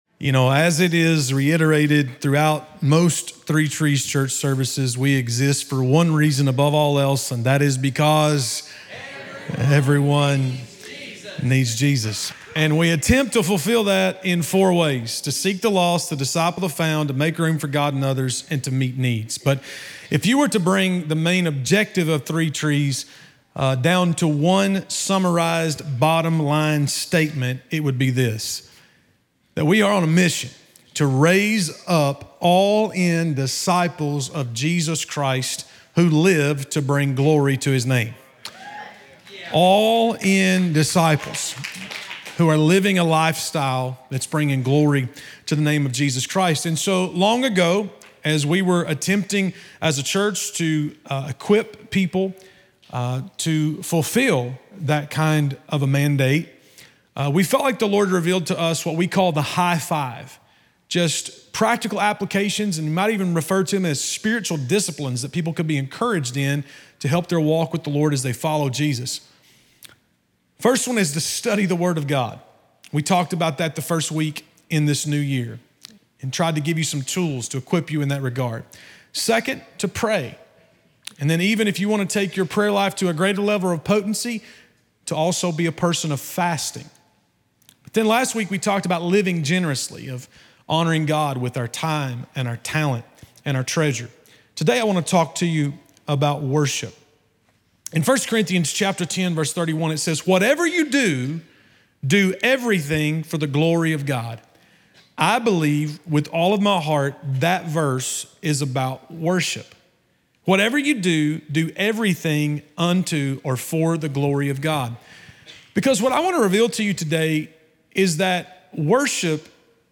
In this sermon, we explore the characteristics of true worship using the acronym W.O.R.S.H.I.P., reflecting on God's worthiness, reverence, intimacy, and more. Discover how worship is not just about singing songs but a lifestyle dedicated to glorifying God through our thoughts, actions, and spirit.